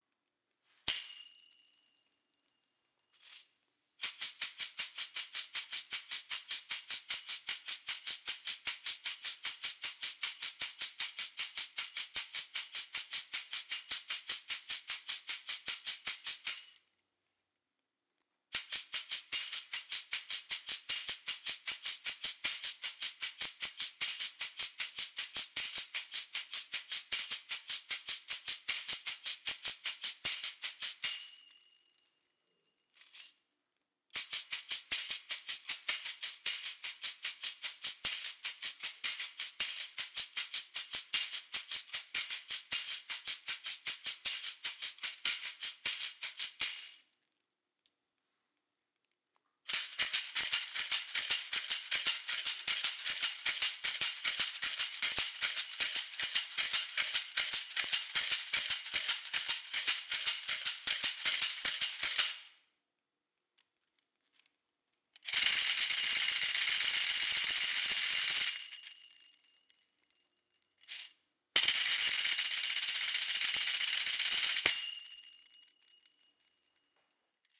MEINL Percussion Headliner® Series Molded ABS Tambourine - 8" (HTT8R)